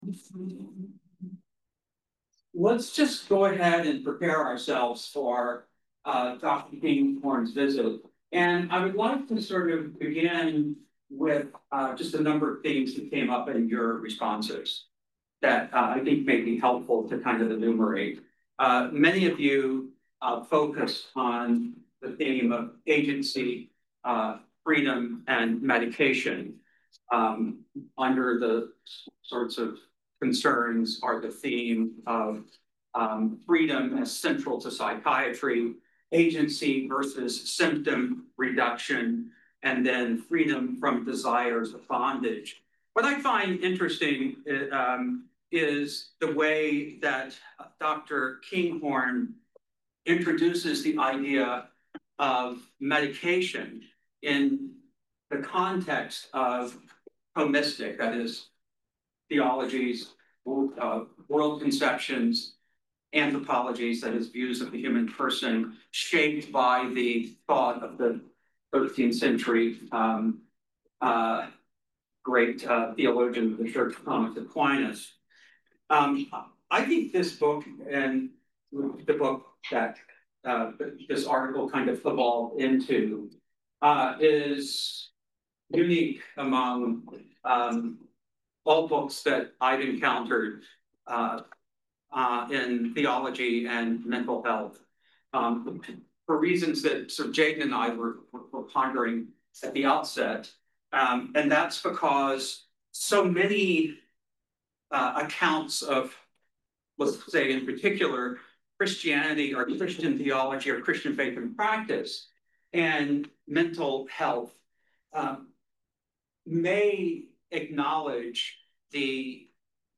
Audio Information Date Recorded: September 9, 2025 Location Recorded: Charlottesville, VA Audio File: Download File » This audio is published by the Project on Lived Theology (PLT).